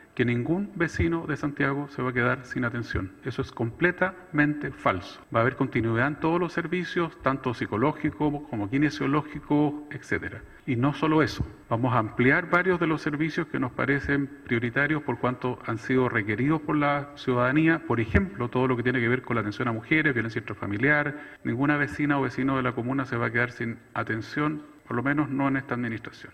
El alcalde no solo dijo que “la administración con más denuncias por maltrato laboral fue la de Irací Hassler (PC)”, sino que descartó de lleno que se termine con programas o servicios para los vecinos.